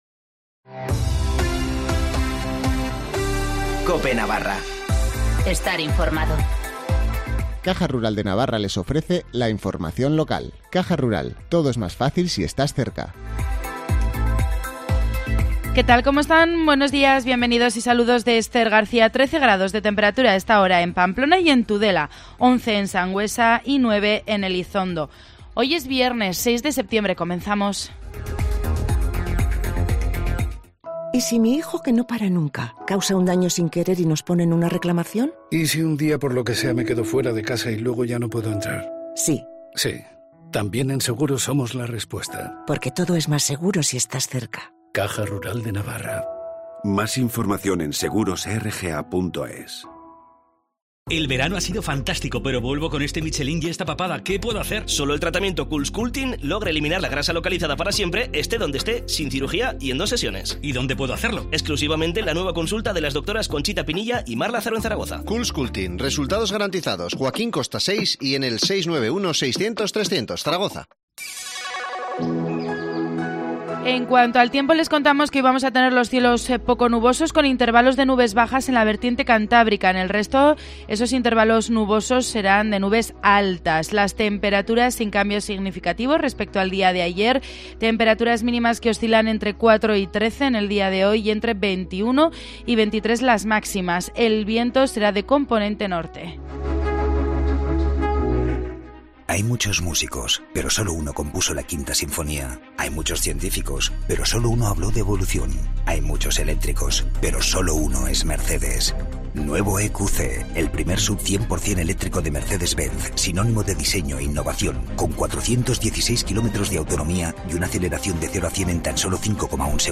Informativo matinal del 6 de septiembre